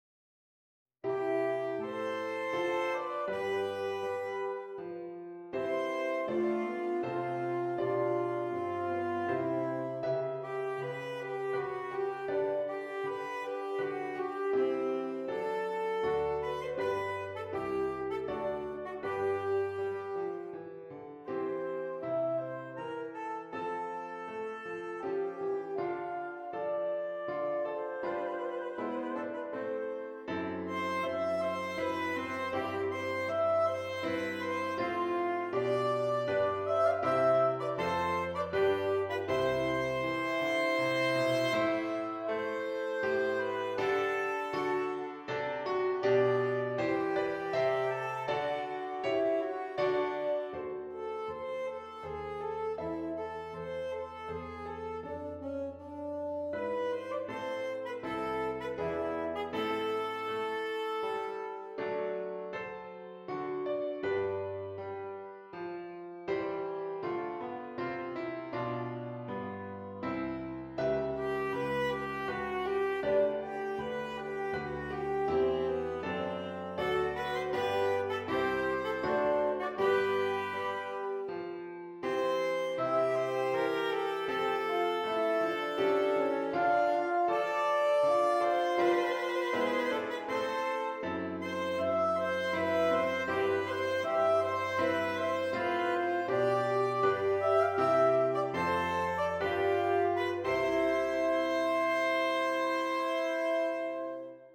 2 Alto Saxophones and Piano
This beautiful melody